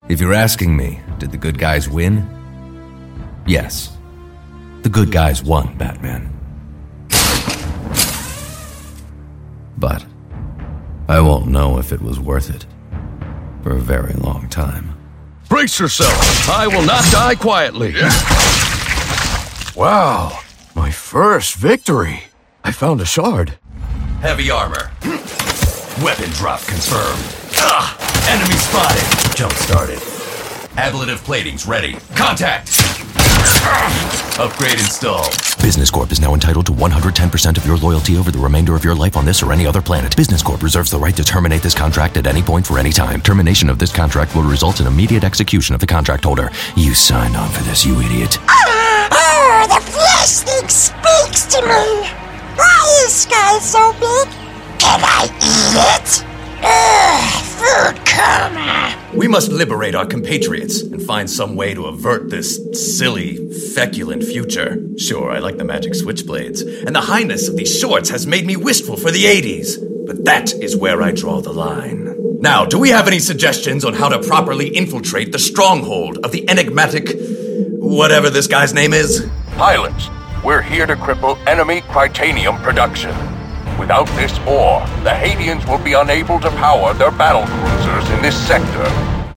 Smart, funny, versatile baritone suitable for character, corporate, and commercial work. Natural, smooth, sonorous, and warm delivery.
englisch (us)
Sprechprobe: Sonstiges (Muttersprache):